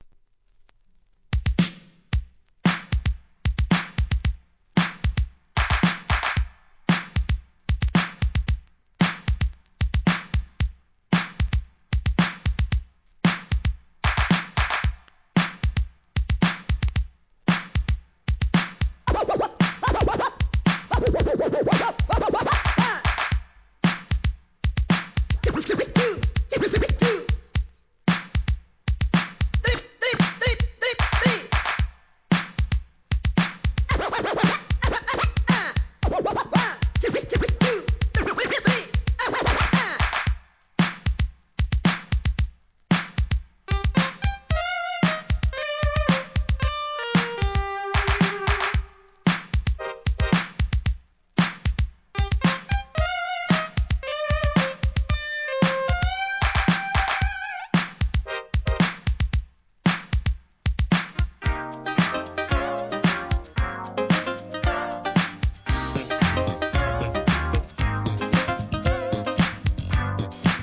HIP HOP/R&B
スクラッチを絡めたFUNK INST.物!!